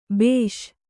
♪ bēṣ